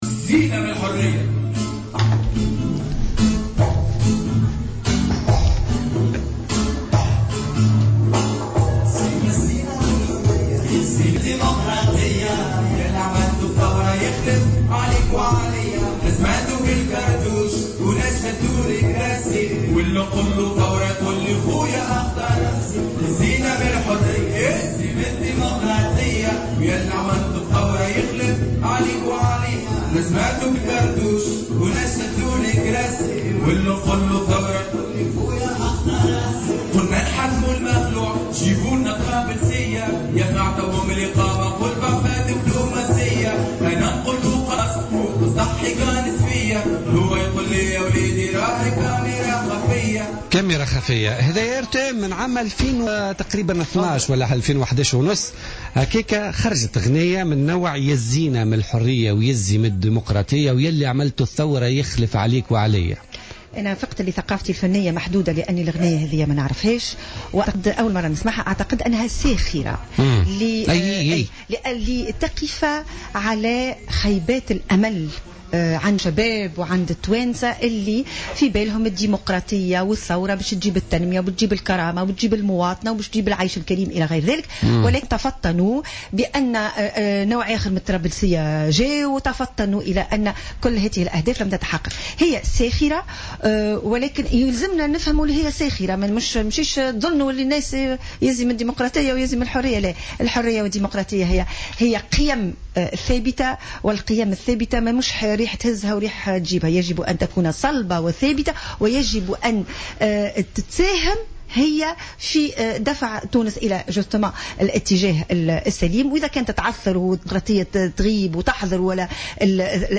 La secrétaire générale du parti républicain, Maya Jeribi, était l’invité de Politica du vendredi 27 novembre 2015.